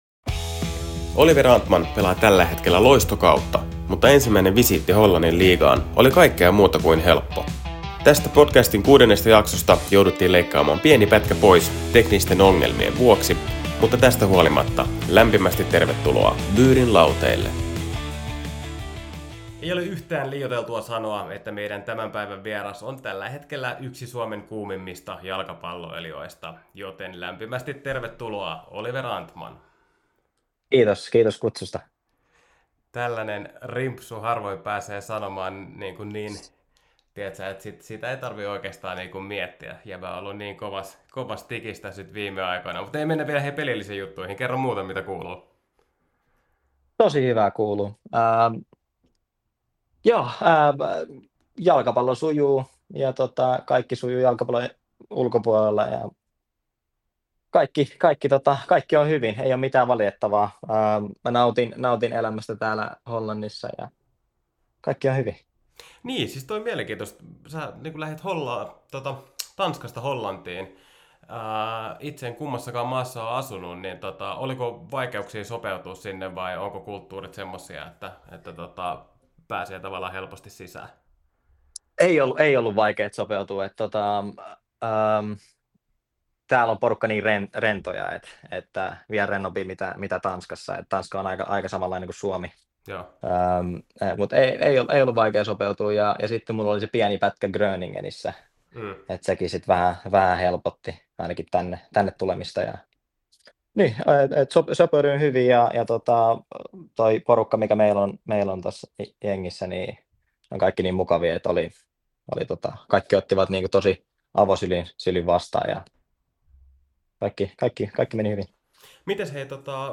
Mitä kuuluu yhdelle Suomen kuumimmista jalkapalloilijoista tällä hetkellä? Se selviää, kun kuuntelet Byyrin Lauteilla -podcastin kuudennen jakson, jossa vieraana on Oliver Antman. Jaksosta jouduttiin leikkaamaan pieni osa pois teknisten ongelmien vuoksi.